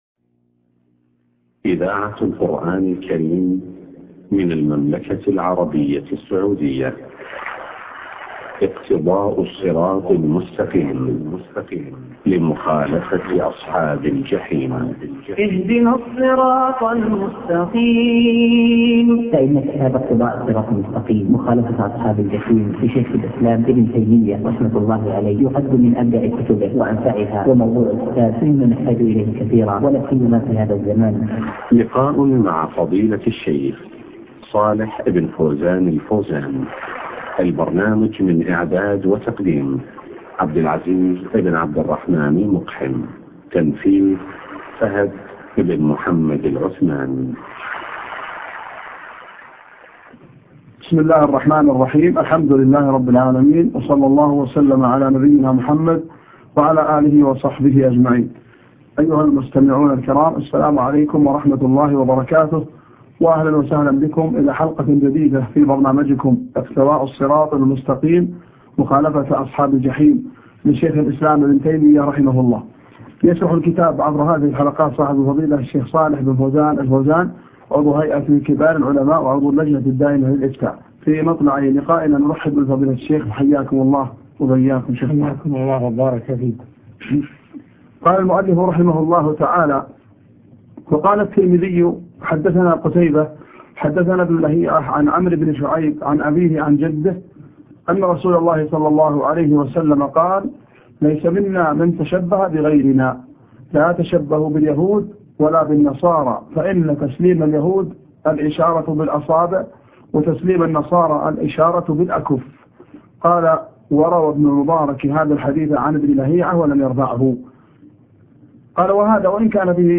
اقتضاء الصراط المستقيم شرح الشيخ صالح بن فوزان الفوزان الدرس 32